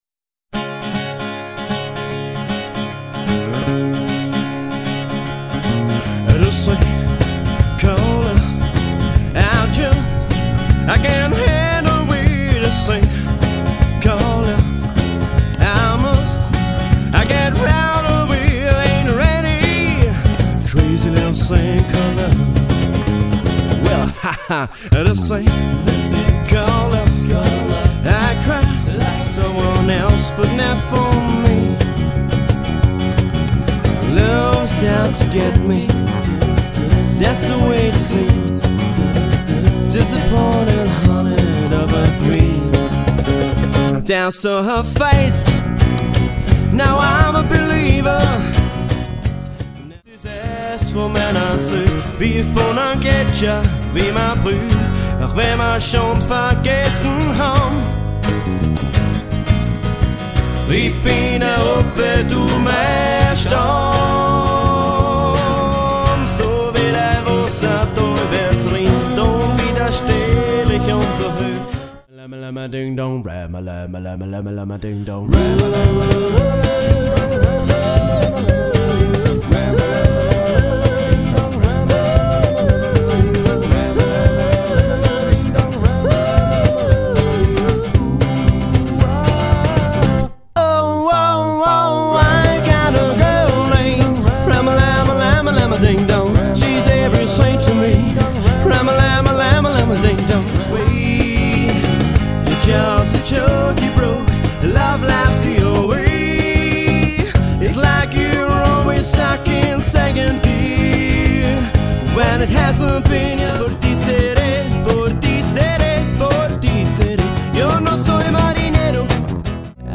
Trio